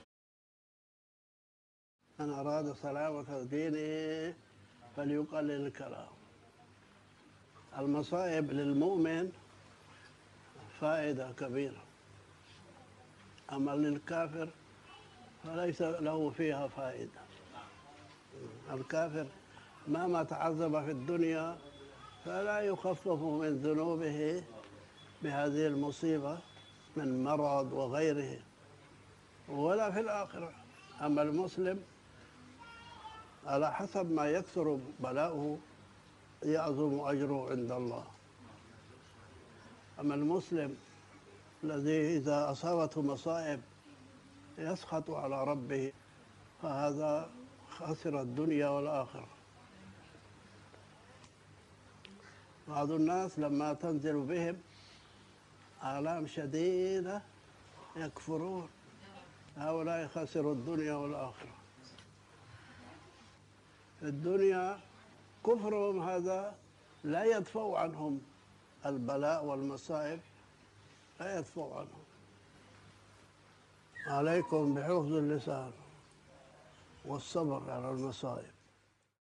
من نصائح العلامة المحدث الشيخ عبد الله الهرري